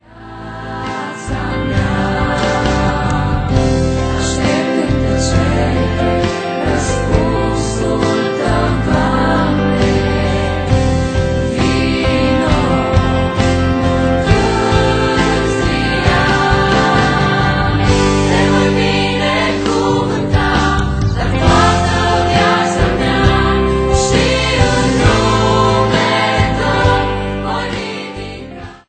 intr-un stil propriu si revigorant